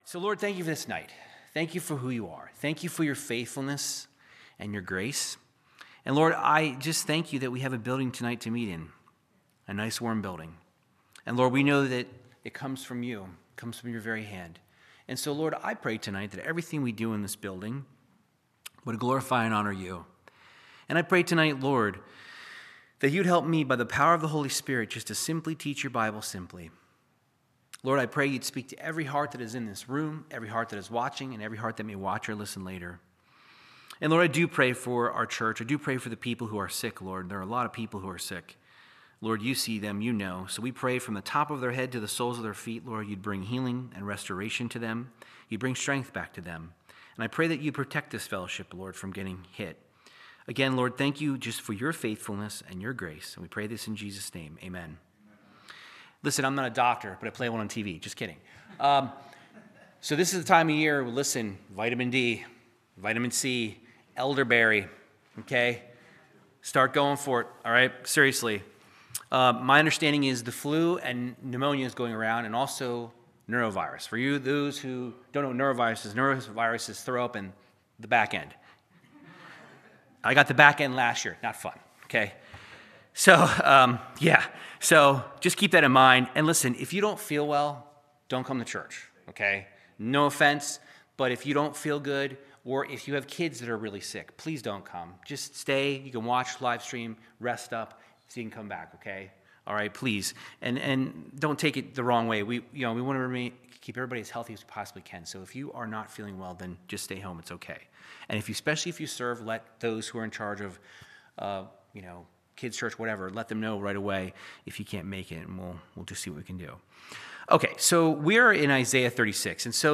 Verse by verse Bible teaching through the book of Isaiah chapters 36 through 39